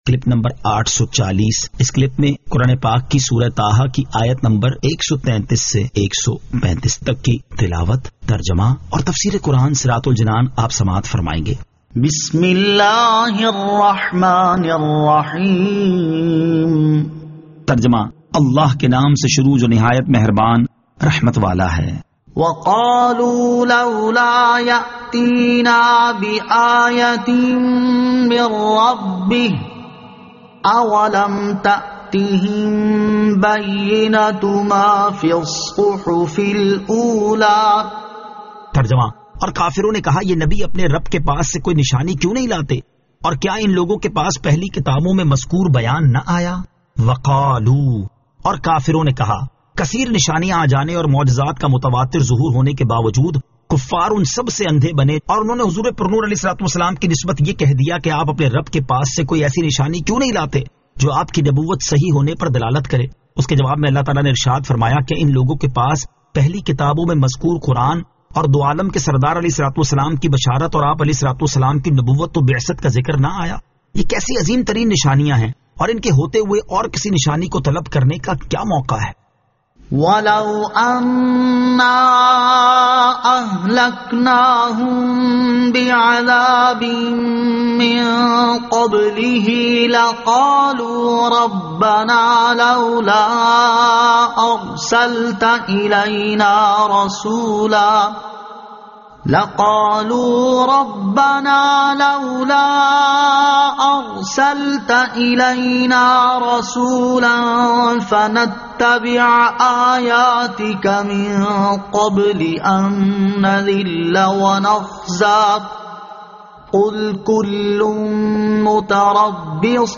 Surah Taha Ayat 133 To 135 Tilawat , Tarjama , Tafseer